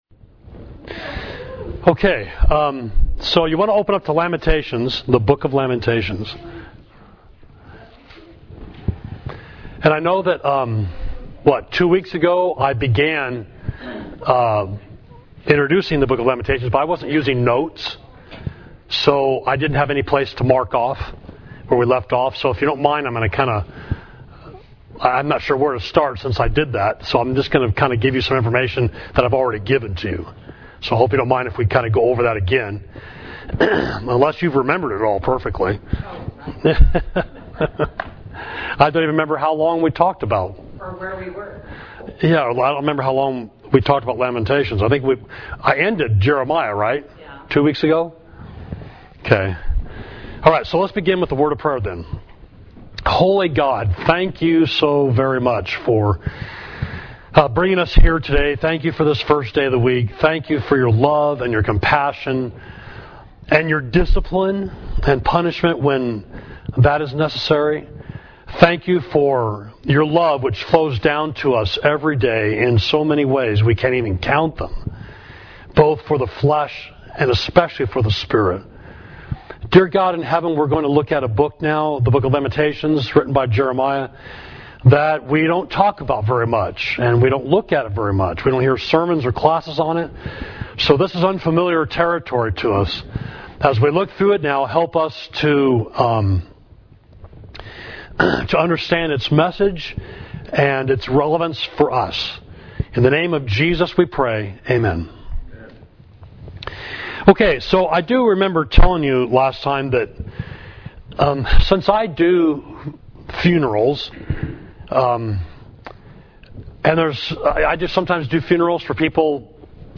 Class: Introduction to the Lamentations of Jeremiah